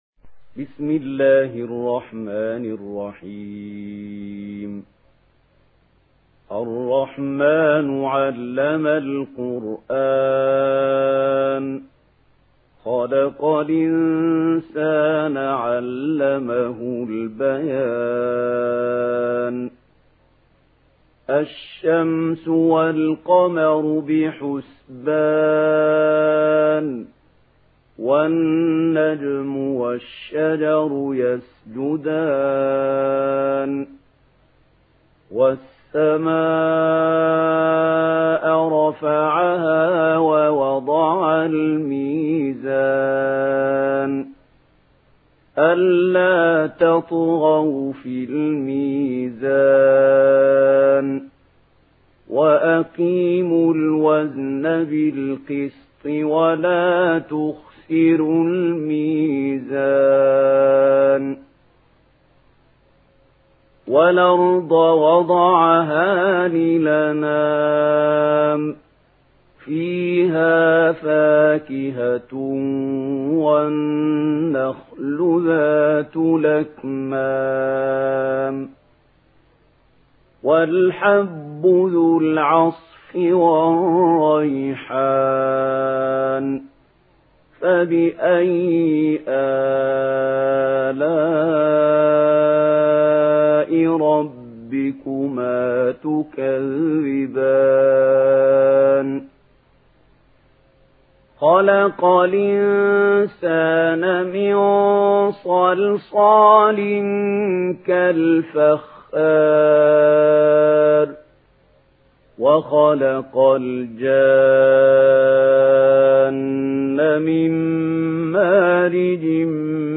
Surah Rahman MP3 in the Voice of Mahmoud Khalil Al-Hussary in Warsh Narration
Murattal Warsh An Nafi